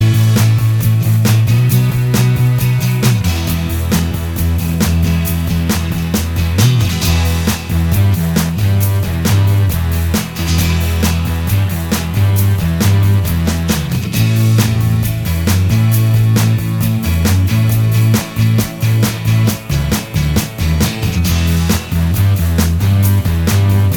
Minus Electric Guitar Glam Rock 3:46 Buy £1.50